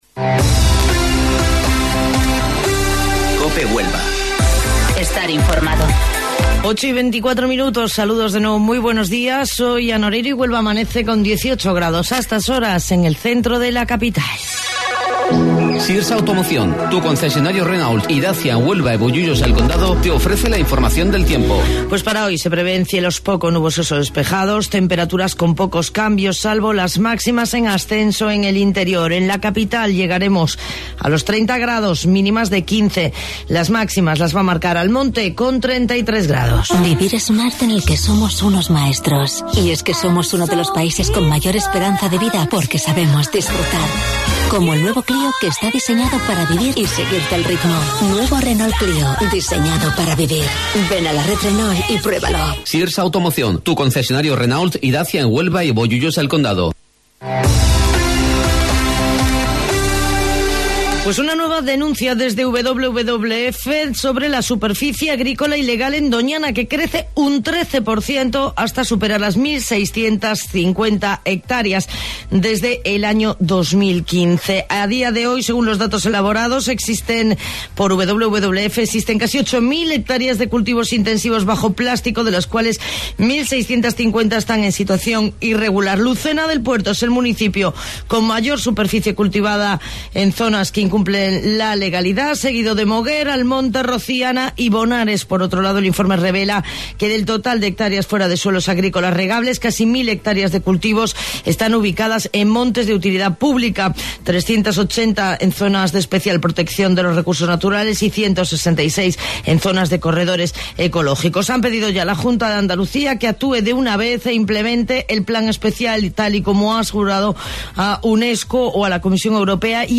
AUDIO: Informativo Local 08:25 del 26 de Septiembre